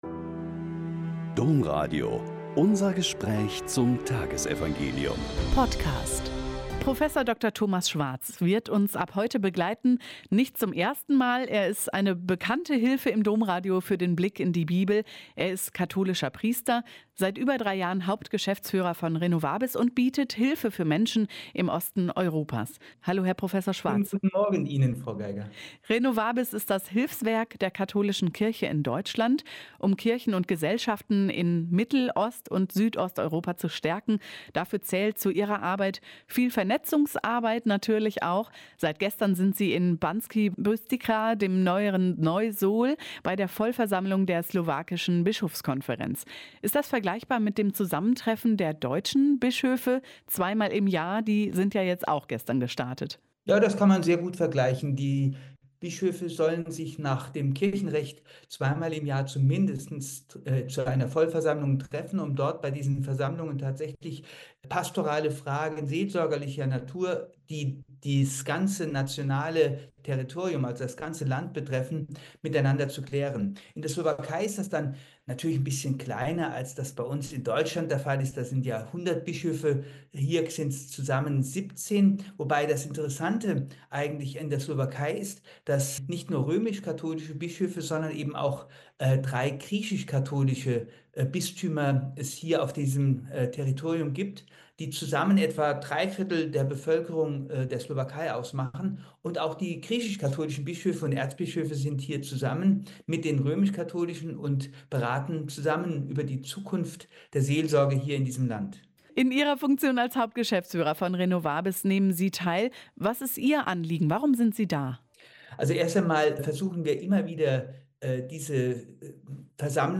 Mt 25,31-46 - Gespräch